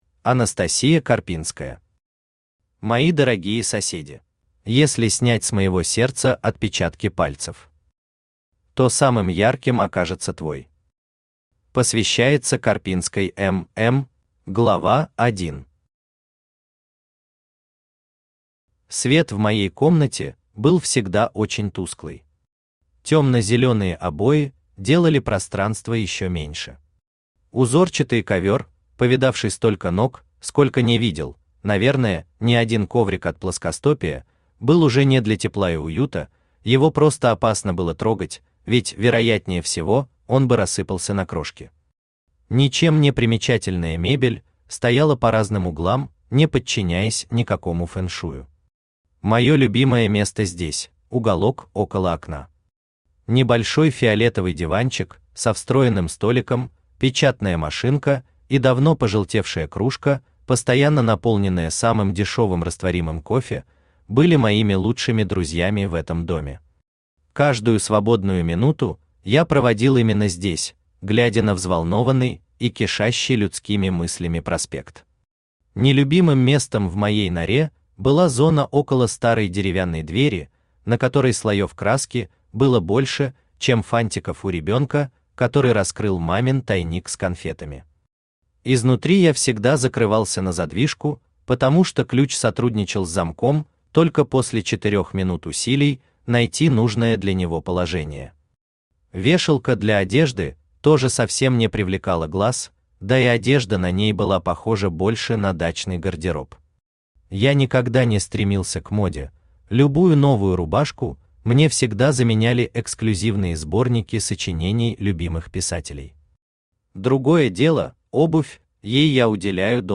Аудиокнига Мои дорогие соседи | Библиотека аудиокниг
Aудиокнига Мои дорогие соседи Автор Анастасия Карпинская Читает аудиокнигу Авточтец ЛитРес.